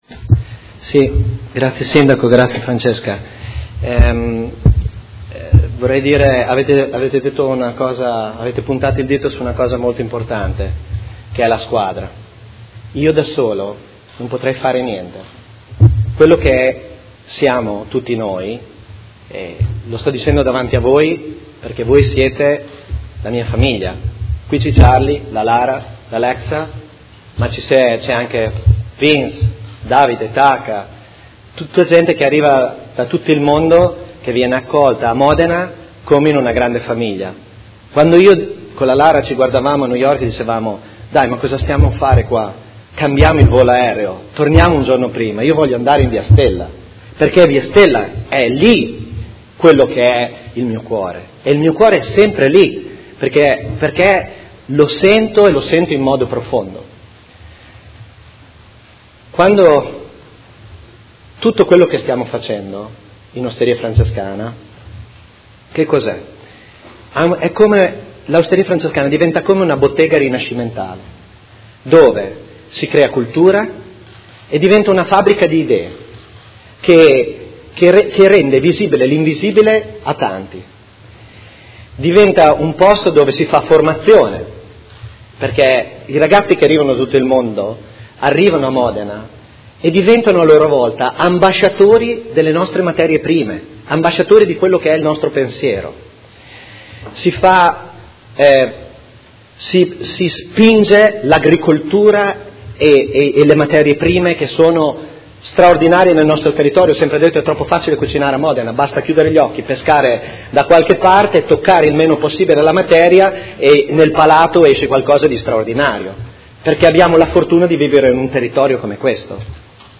Seduta del 7 luglio. Intervento di ringraziamento dello chef stellato massimo Bottura